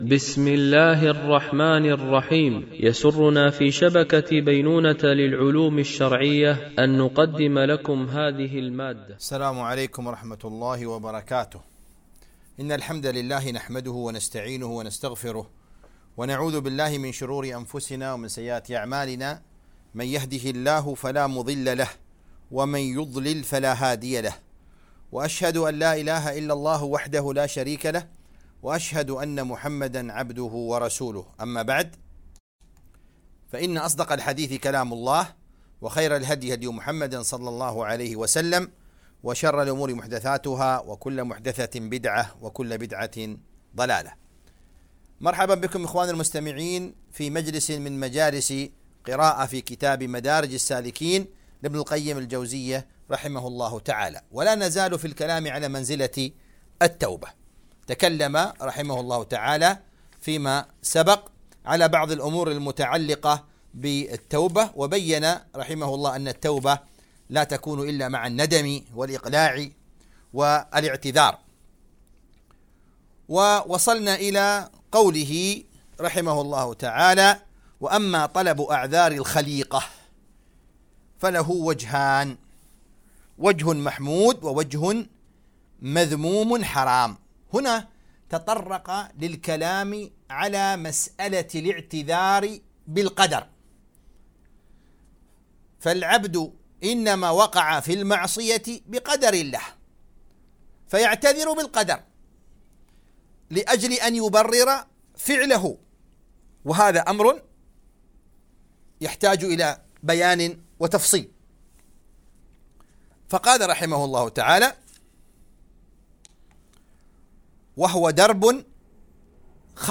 قراءة من كتاب مدارج السالكين - الدرس 25